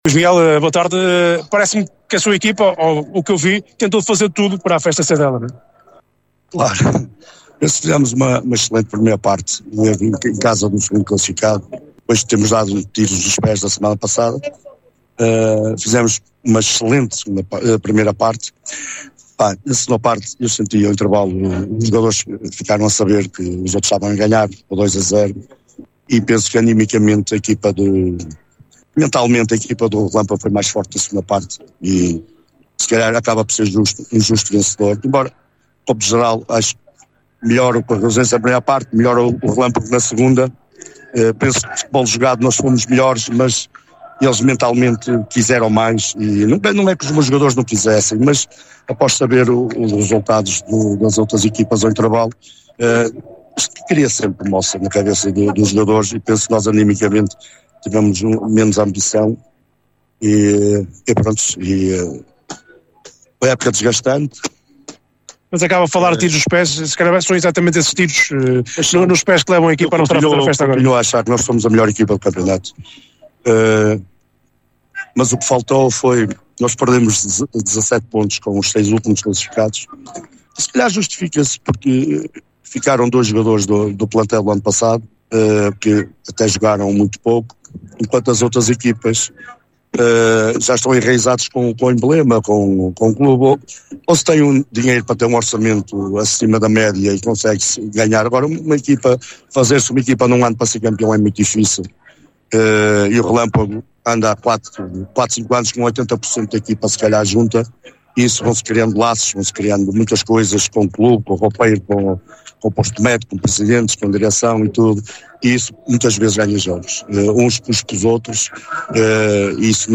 AtualidadeDesportoDestaquesEntrevistasNotíciasRegistos